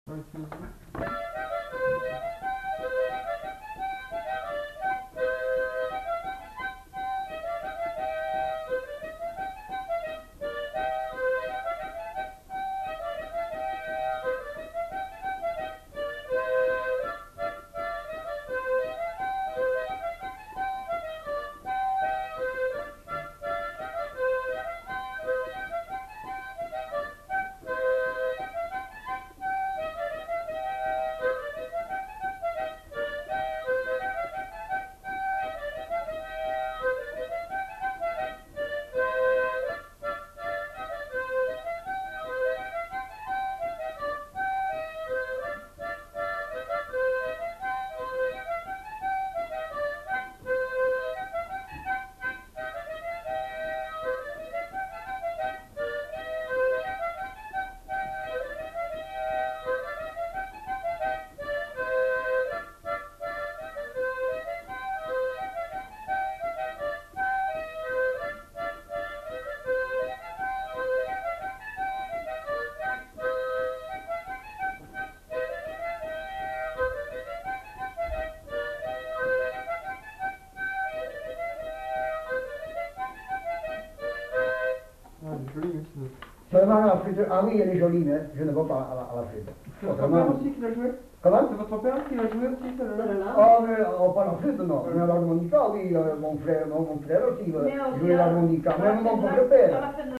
Répertoire d'airs à danser joué à la flûte à trois trous et à l'harmonica
Scottish